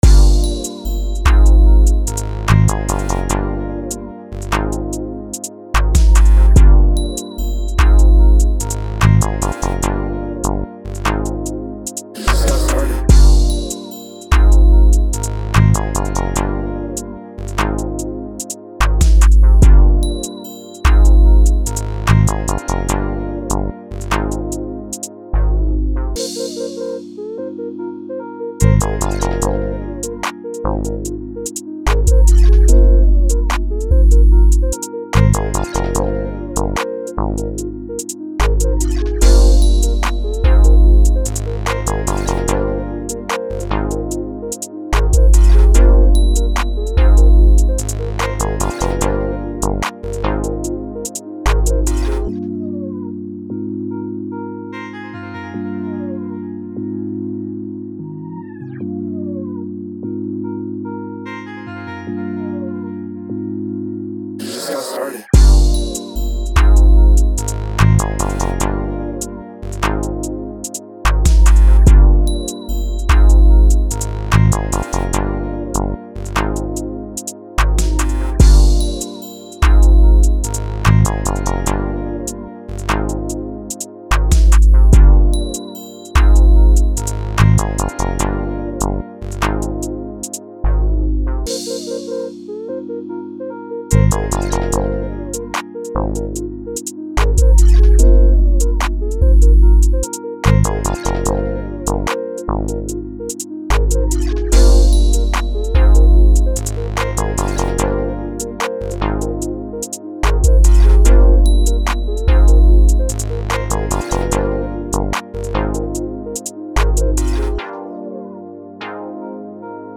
Untitled (Drum Kit)
has everything you need to make unique new jazz and hoodtrap beats from scratch! this kit really stands out with its unique 808s and lead oneshots.